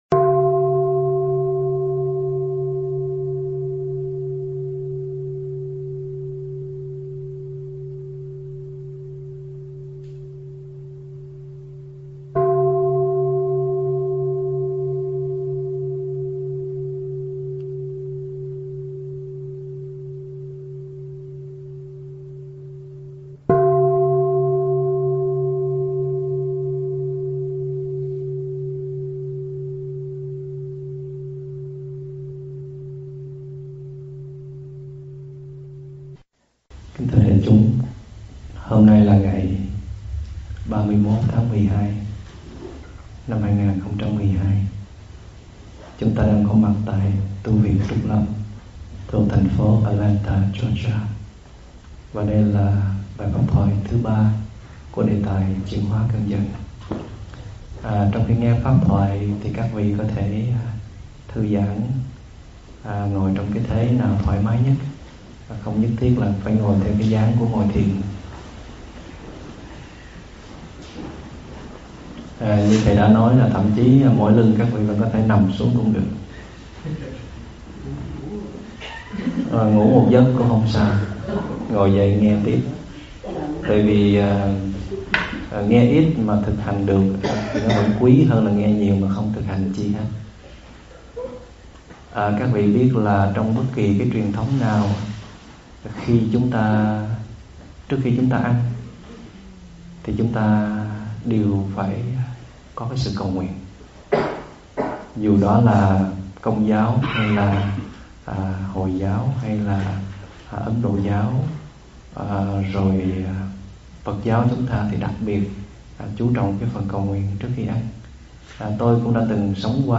giảng tại tu viện Trúc Lâm ngày 31 tháng 12 năm 2012
Thuyết pháp